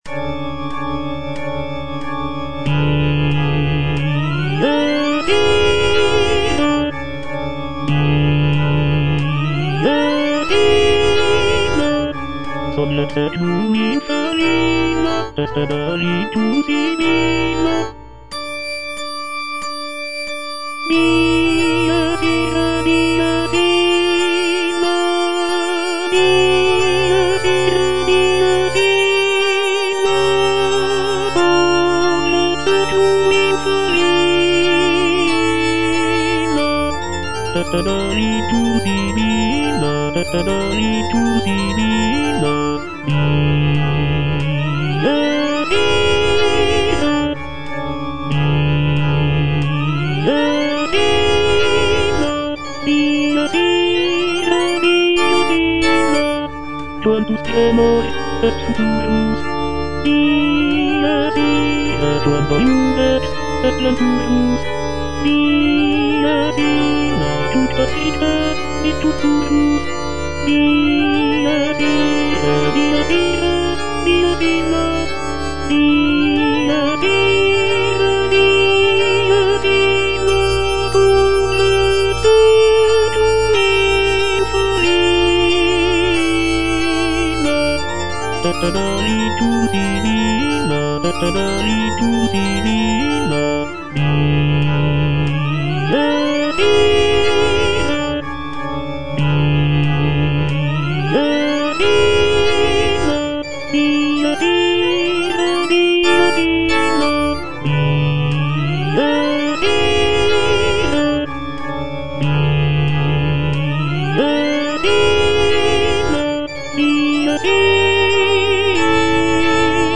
F. VON SUPPÈ - MISSA PRO DEFUNCTIS/REQUIEM Dies irae - Tenor (Voice with metronome) Ads stop: auto-stop